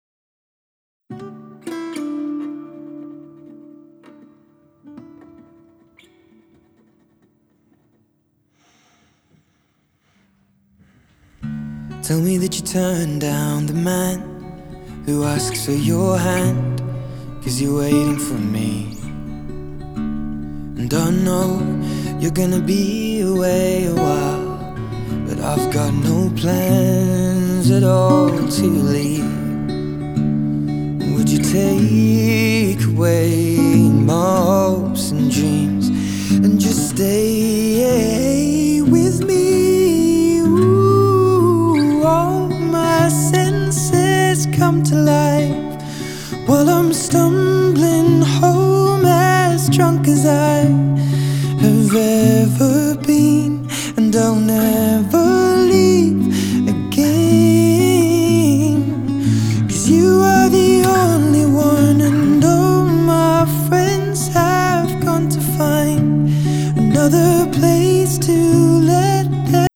(1:10 CD quality)